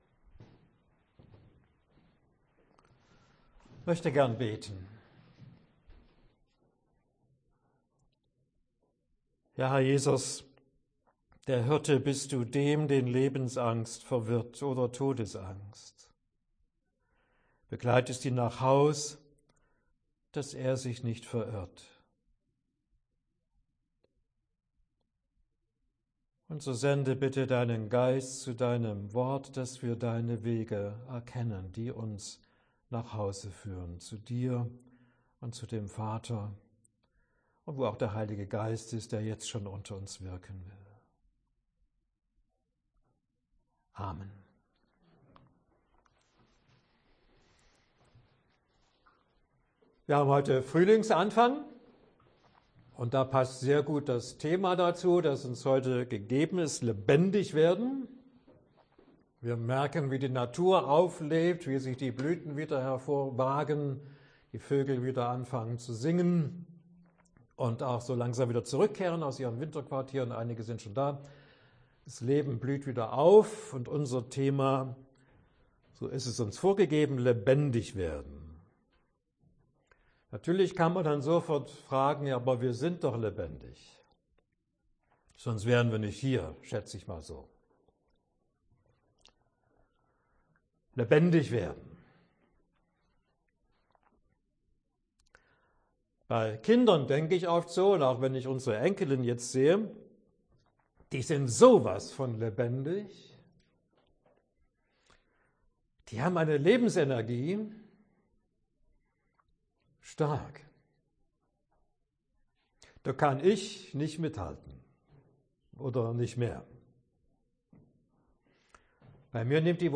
Das Manuskript können Sie HIER NACHLESEN!(Dauer des Vortrages: Ca. 48 Minuten)